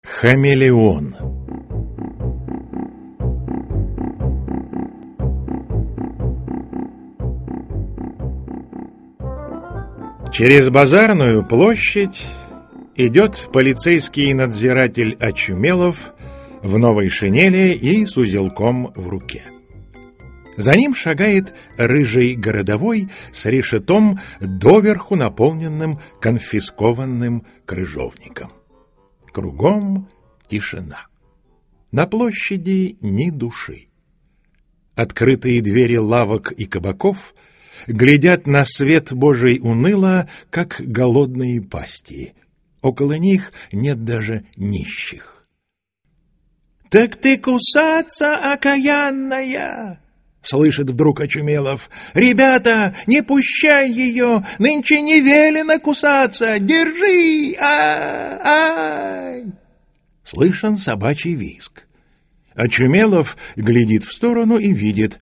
Аудиокнига Хамелеон. Избранные рассказы | Библиотека аудиокниг
Избранные рассказы Автор Антон Чехов Читает аудиокнигу Евгений Киндинов.